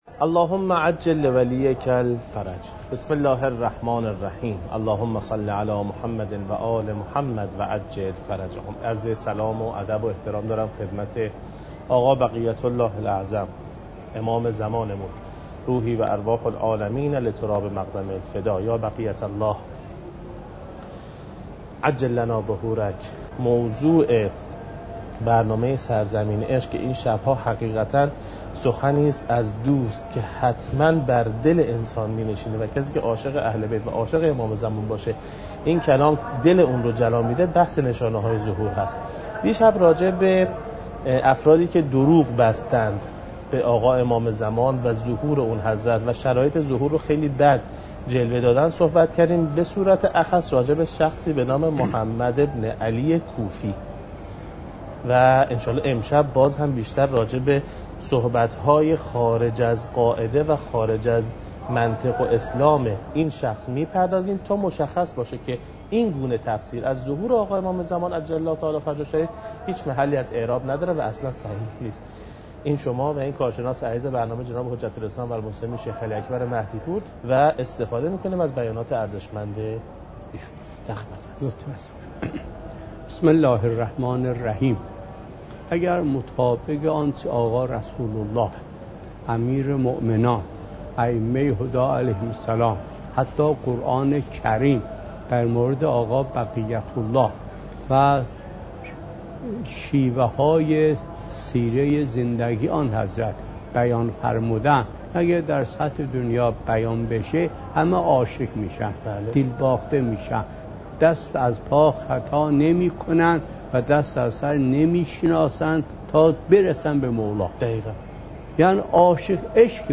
حجم: 11.8 MB | زمان: 50:30 | تاریخ: 1439هـ.ق | مکان: کربلا